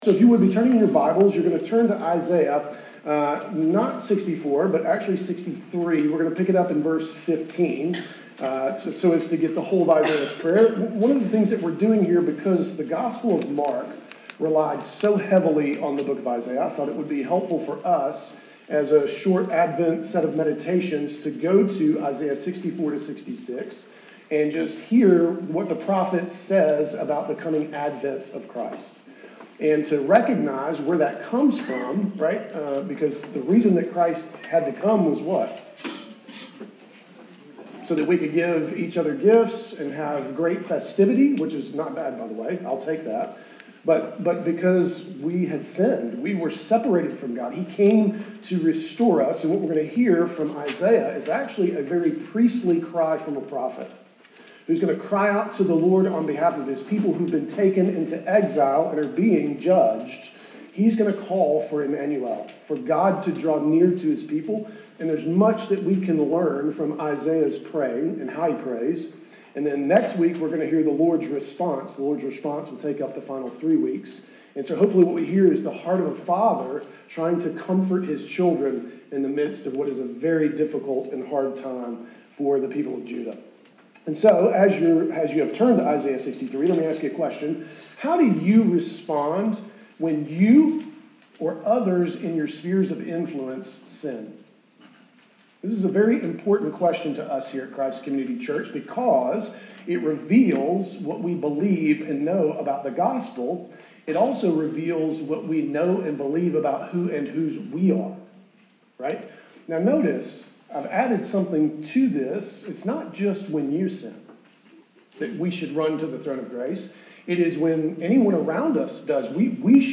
Weekly Sermons from Christ Community Church